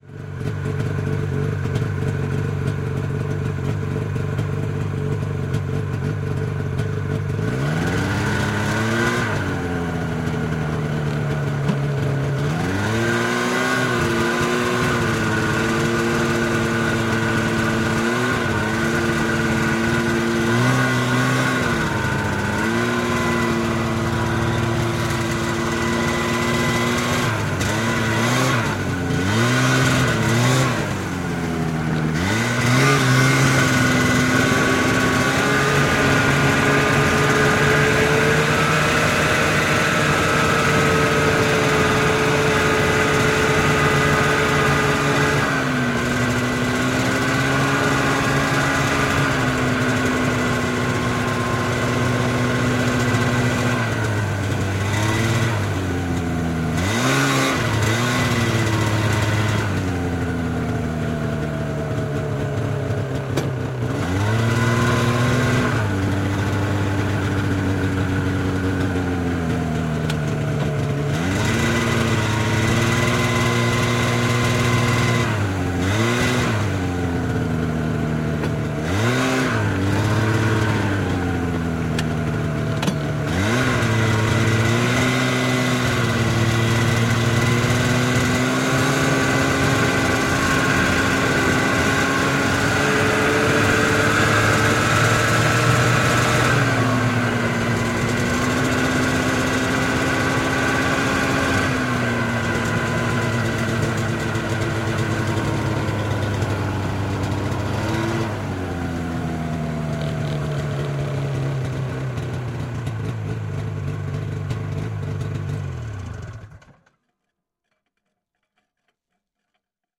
На этой странице собраны звуки снегохода: рев мотора, скрип снега под гусеницами, свист ветра на скорости.
Атмосферный гул снегохода в движении